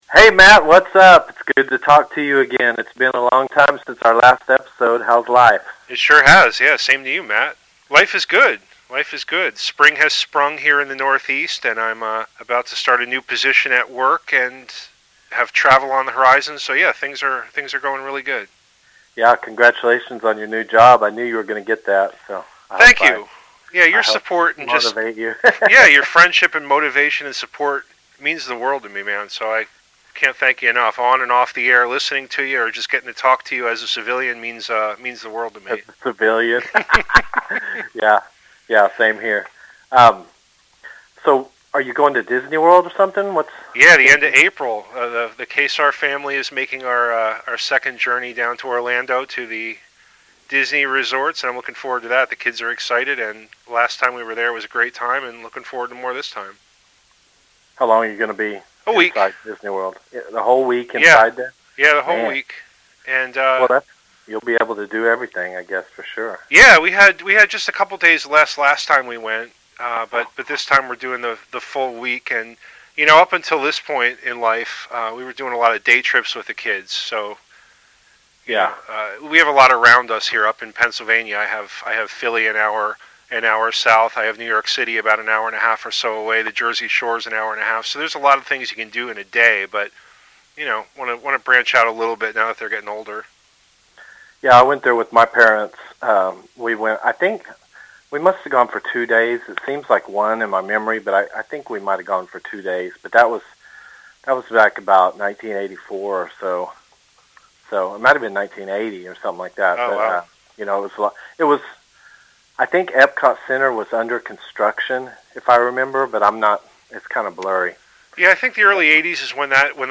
We talked guitars, music, movies, classic television and all points in between. There are also a few musical surprises included.